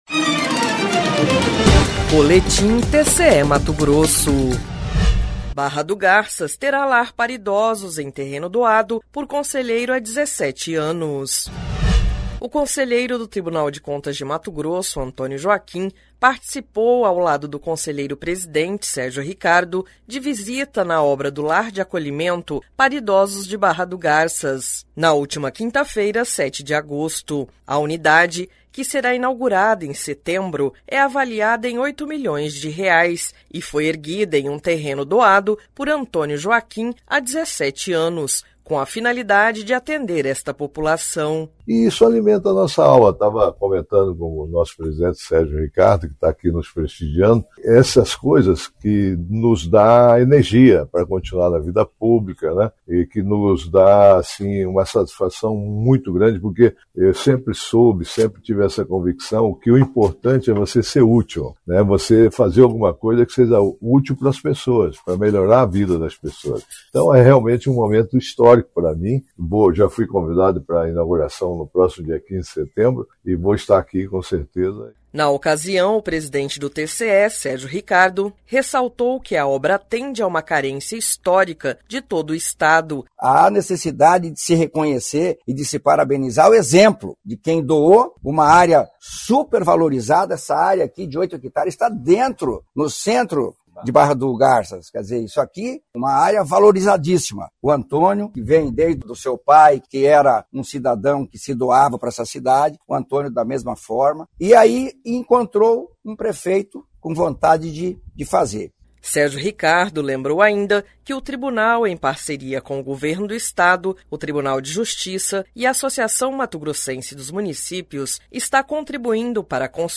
Sonora: Antonio Joaquim – conselheiro do TCE-MT
Sonora: Sérgio Ricardo – conselheiro-presidente do TCE-MT
Sonora: Adilson Gonçalves - prefeito de Barra do Garças